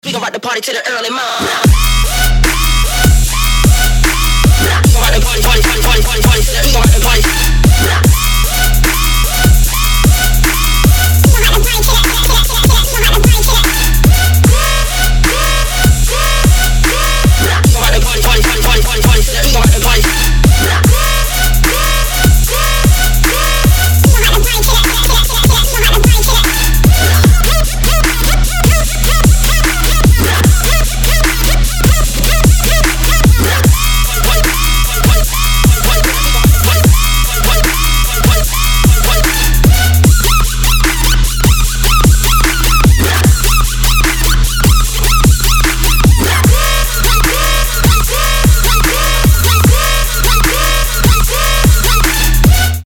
• Качество: 320, Stereo
громкие
жесткие
Electronic
очень громкие
Bass music
дрель